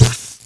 StunHit.ogg